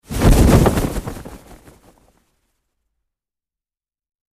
Cloth; Parachute Opening, Various Cloth Flap And Movement.